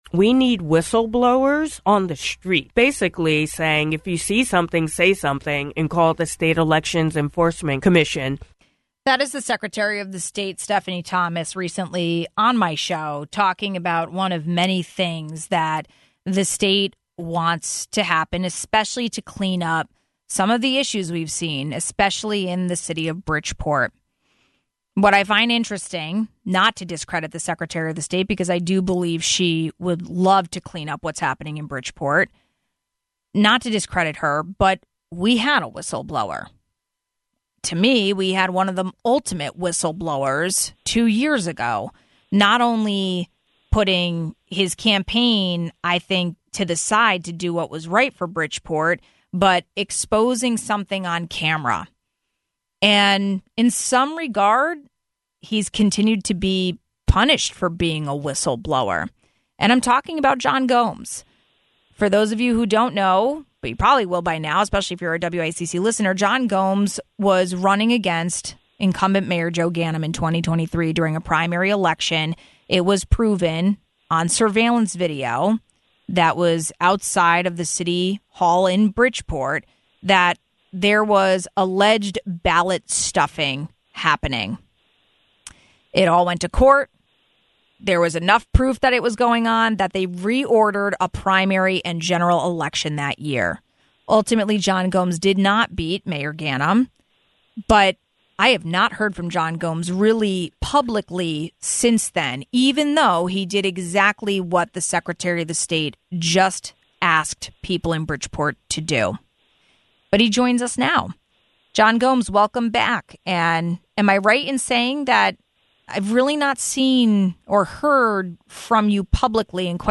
We spoke with him on WICC.